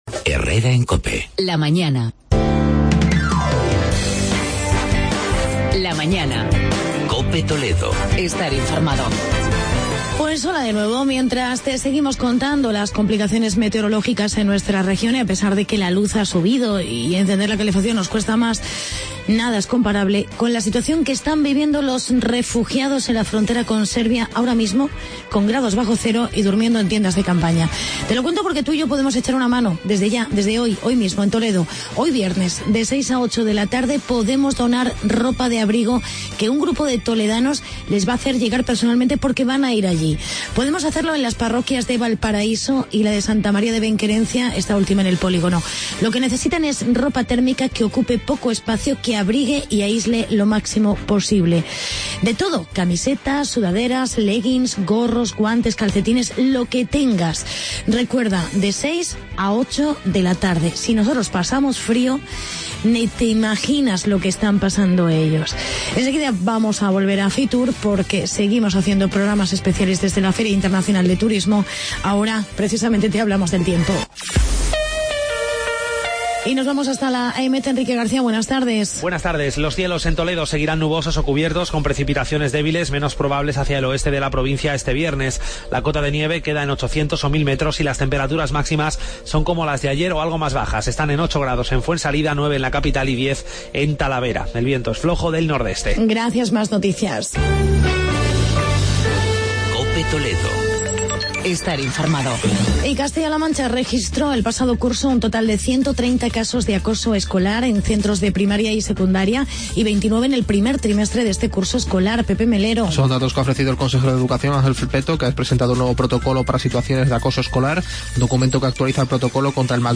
Especial Fitur. Entrevista alcalde de Illescas, José Manuel Tofiño con el año Cisneros.